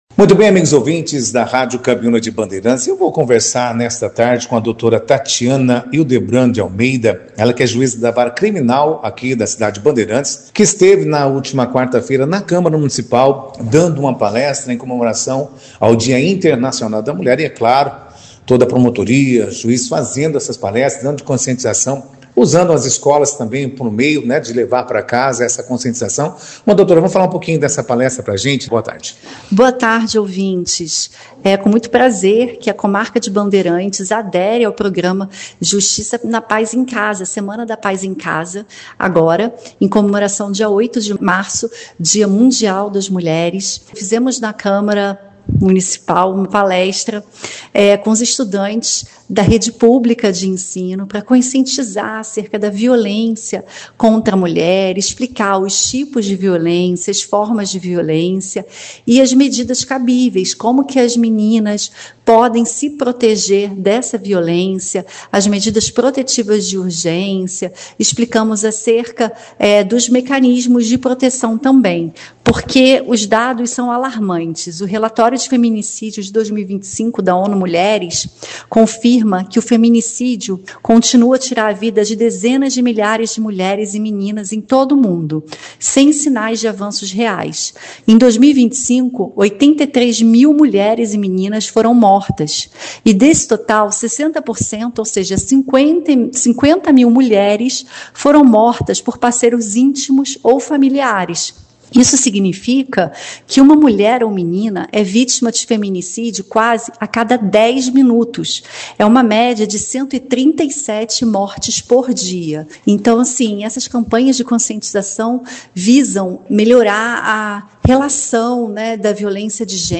Justiça pela Paz em Casa: juíza Tatiana Hildebrandt fala sobre combate à violência contra a mulher em Bandeirantes - Rádio Cabiuna
Em Bandeirantes, a juíza de Direito Tatiana Hildebrandt de Almeida, titular da Vara Criminal, Família e Sucessões, Infância e Juventude e do Juizado Especial Cível, Criminal e da Fazenda Pública da Comarca, participou da 2ª edição do Jornal Operação Cidade, nesta quinta-feira (12), para falar sobre a campanha e a importância da conscientização no combate à violência contra a mulher.